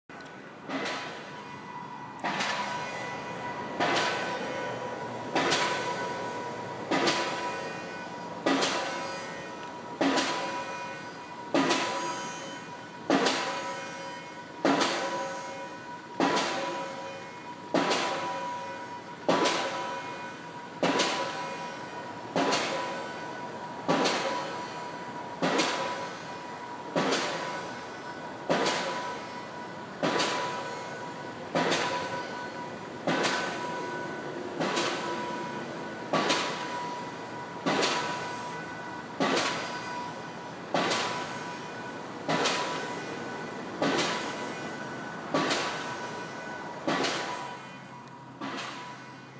Pile Driver
piledriver.m4a